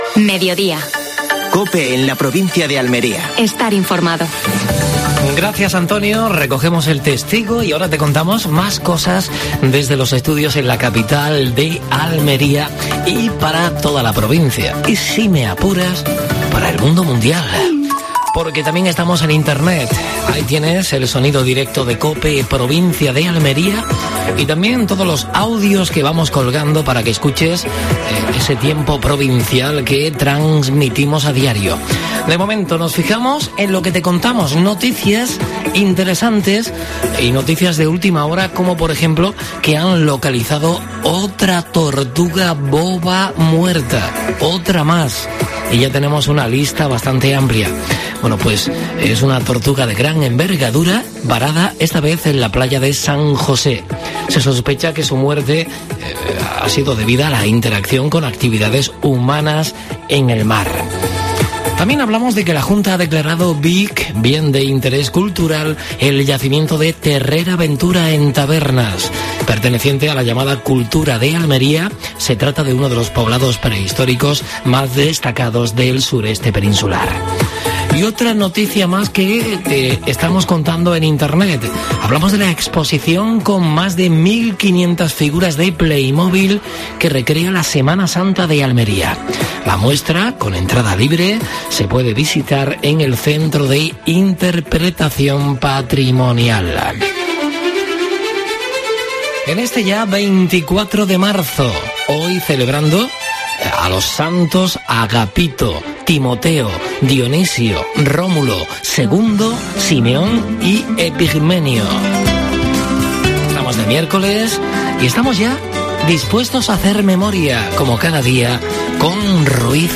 AUDIO: Actualidad en Almería. ¿Qué pasó tal día como hoy en la provincia? Entrevista